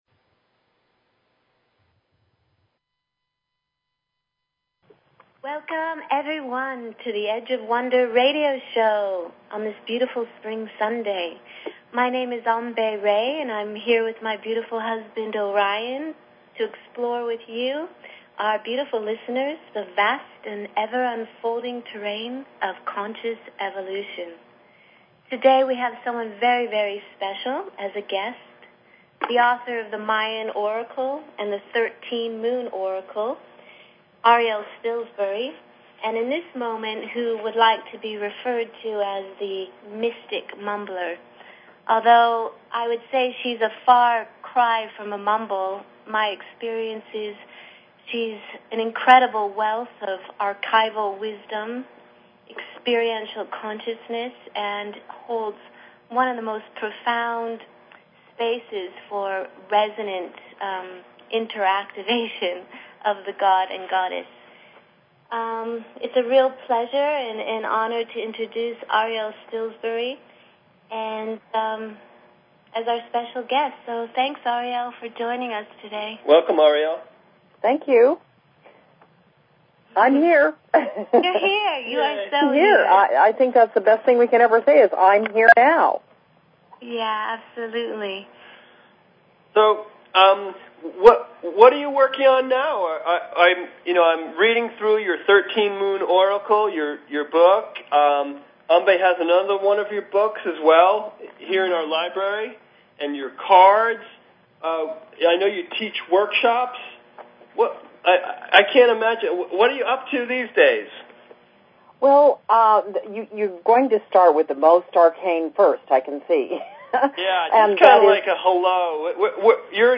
Talk Show Episode, Audio Podcast, Edge_of_Wonder_Radio and Courtesy of BBS Radio on , show guests , about , categorized as